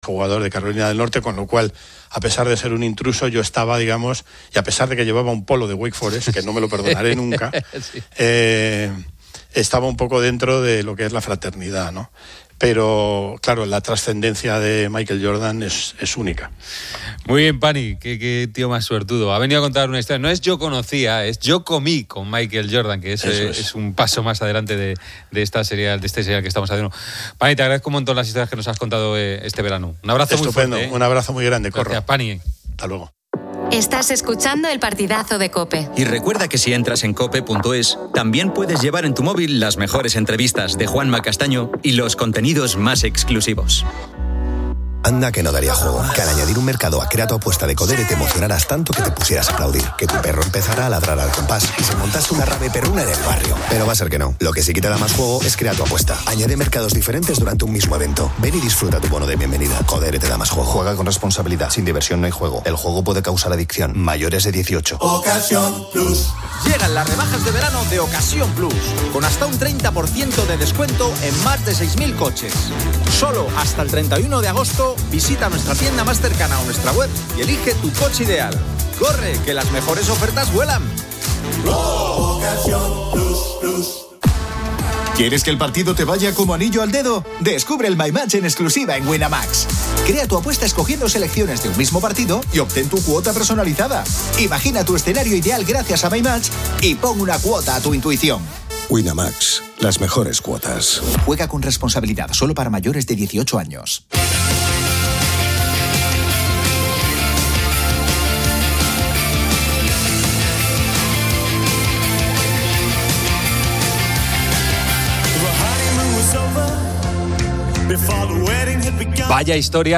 Resumen del audio: El segmento de radio comienza con una anécdota sobre Michael Jordan, seguida de una extensa conversación sobre él.